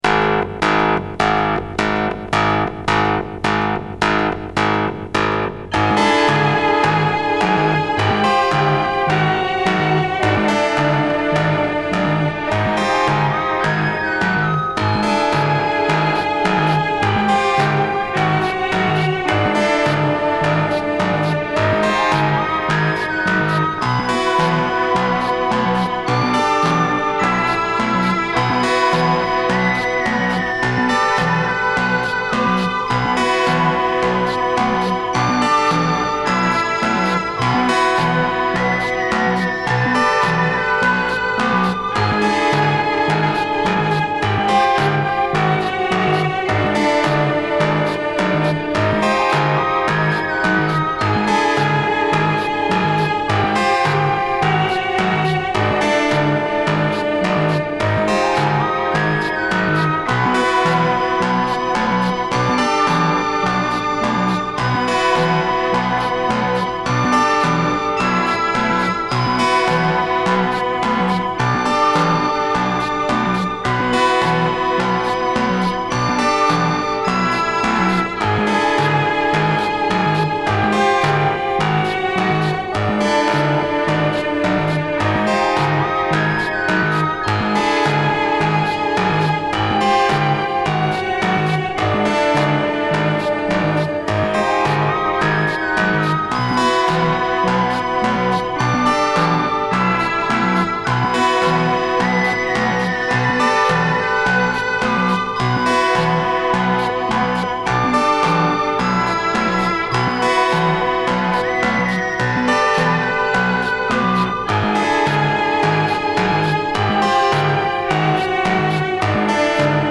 Электронная музыка